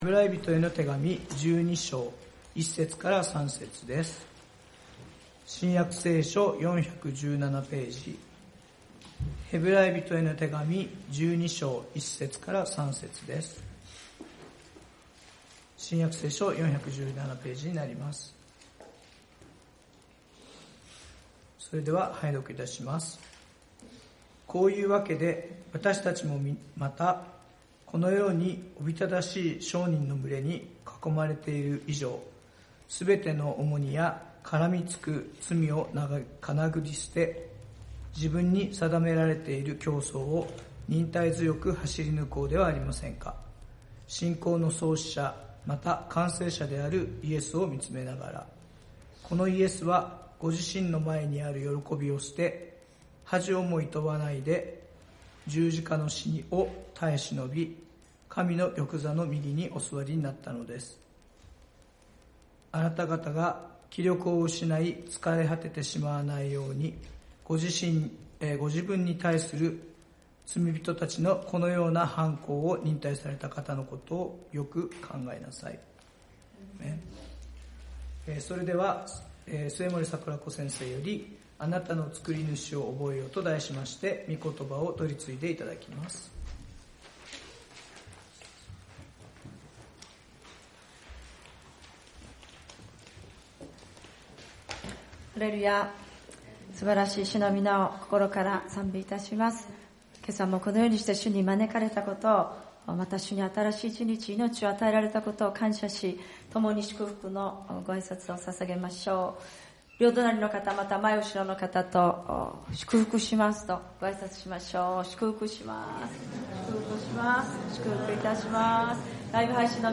聖日礼拝「あなたの造り主を覚えよ」ヘブライ人の手紙 12:1-3